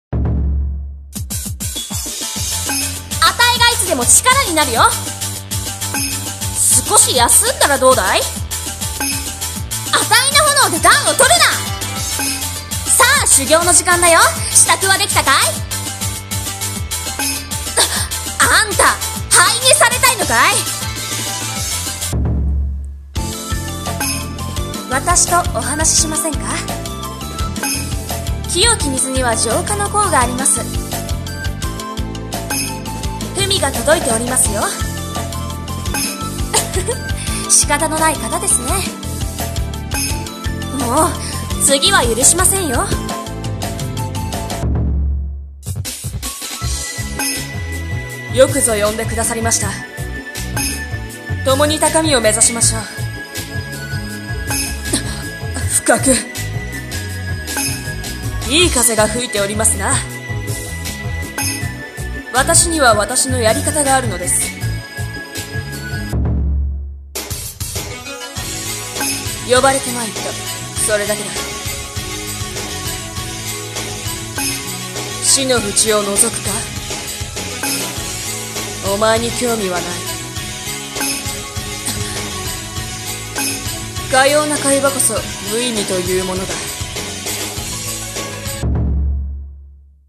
【演じ分け台本】妖怪格ゲーアプリ 女声/少年声用/前編【和風】